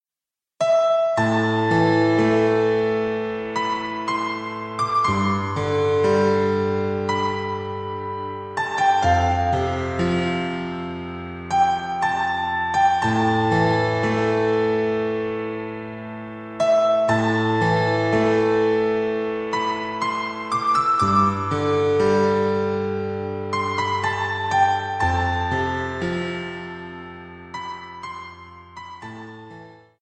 En la etiqueta escribe el texto “Música relajante”.